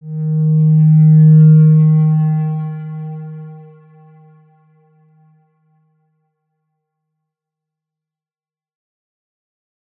X_Windwistle-D#2-mf.wav